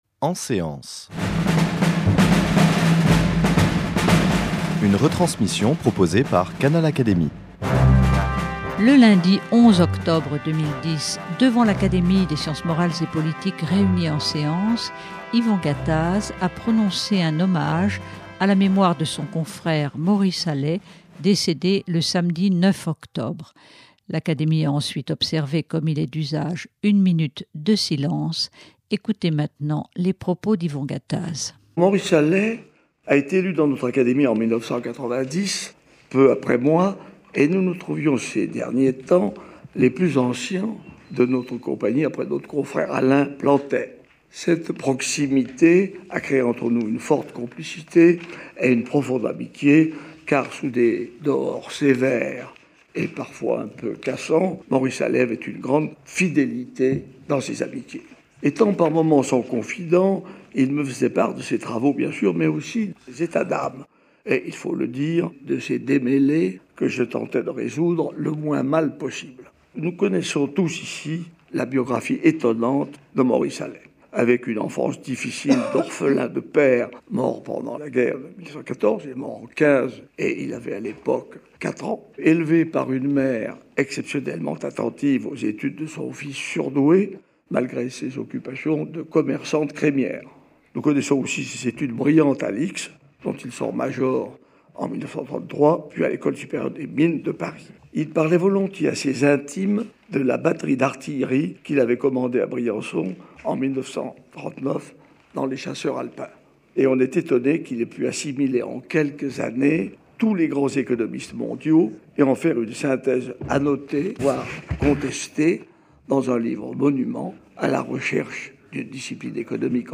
Yvon Gattaz rend hommage à Maurice Allais devant ses confrères, lors de la séance de l’Académie des sciences morales et politiques du lundi 11 octobre 2010, l’économiste Prix Nobel étant décédé le samedi précédent. Cet hommage permet de rappeler que Maurice Allais fut également un éminent physicien.